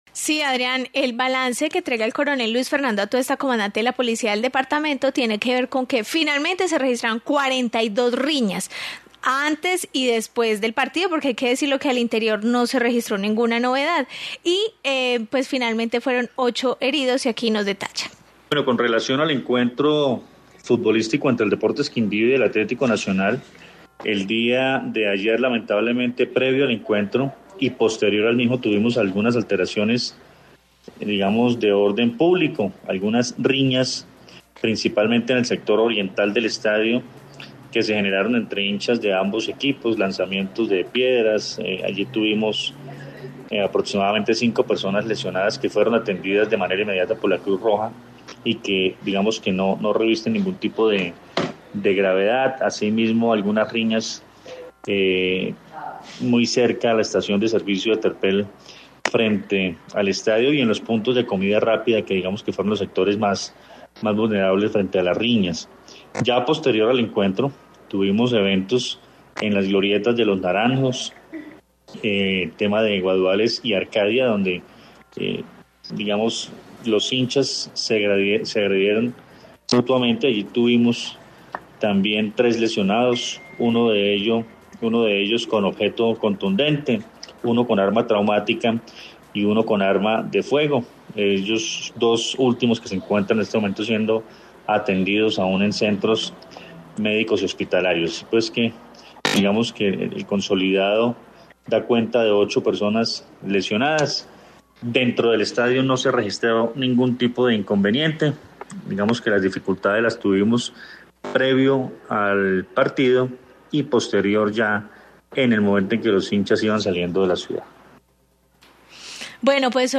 Informe sobre balance de riñas